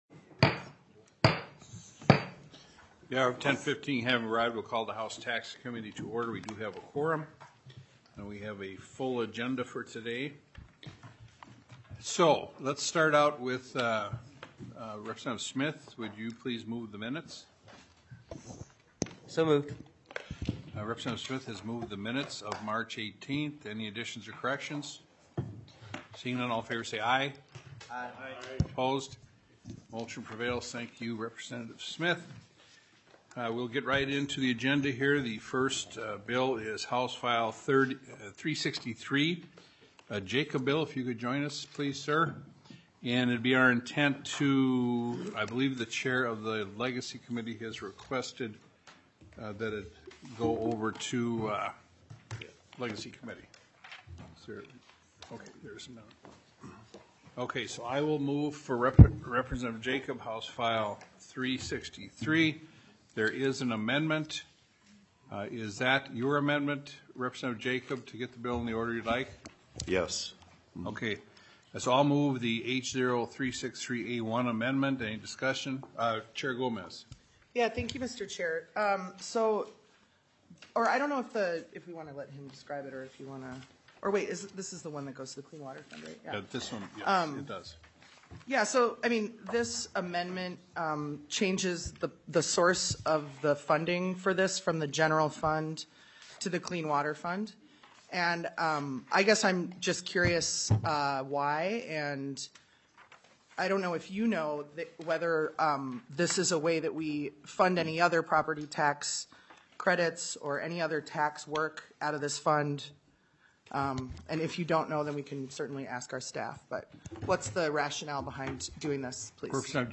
Taxes SIXTEENTH MEETING - Minnesota House of Representatives